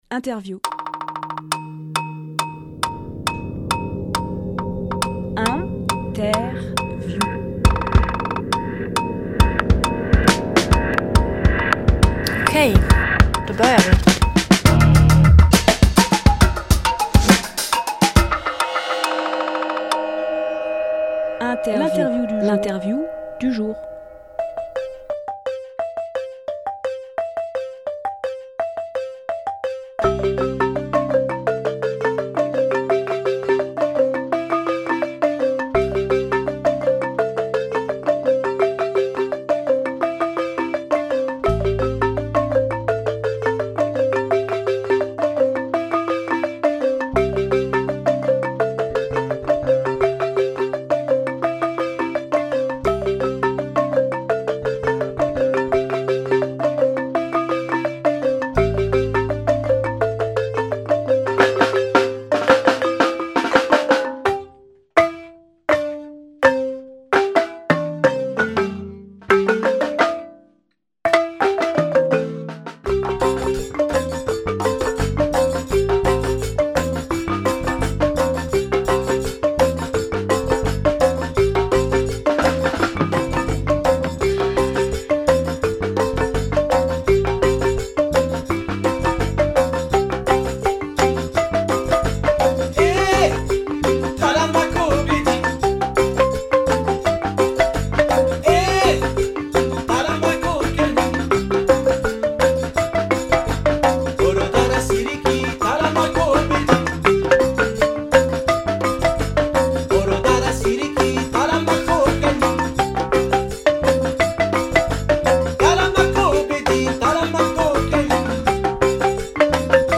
Emission - Interview Soirée Afrika et stage avec Saly Danse Publié le 2 novembre 2018 Partager sur…
Lieu : Studio RDWA